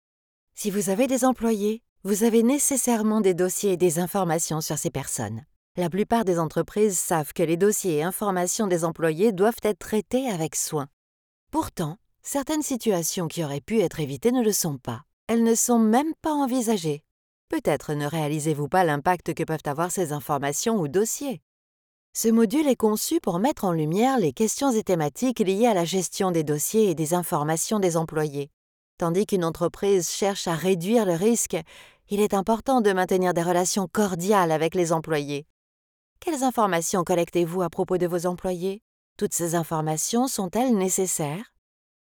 She has a Voice that “speaks” to everyone, a Voice that immediately makes people feeling comfortable with, a Voice that brings everyone's attention.
Voice Tags: Reassuring, impactful, cheerful, young and fresh, authoritative, joyful, smart, trustworthy, believable, catchy, playful, educational, serious, neutral, natural, classy, upbeat...
Sprechprobe: eLearning (Muttersprache):
E-Learning IAPP-Protection-Données.mp3